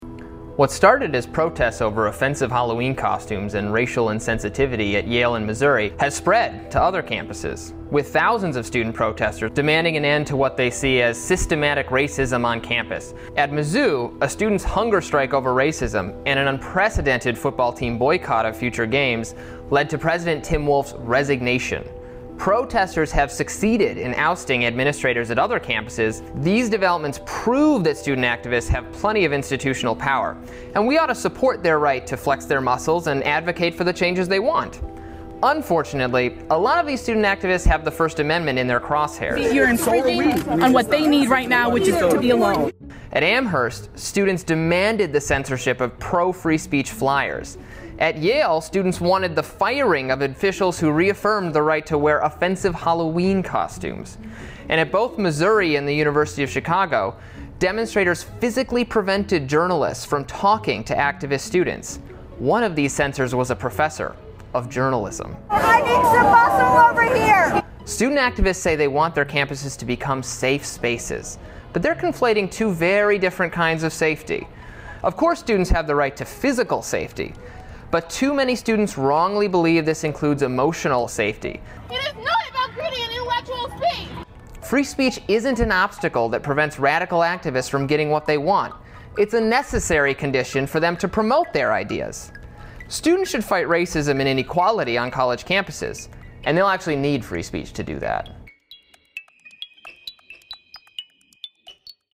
Music by Podington Bear.